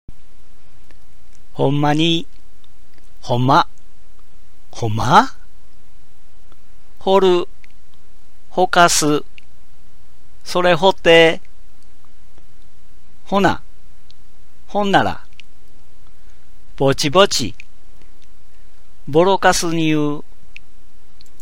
私は河内育ちの大阪弁を話しますが、聞いて分かりますか
引き続いて、大阪弁の独特の言葉をピックアップして、音声を録音しています。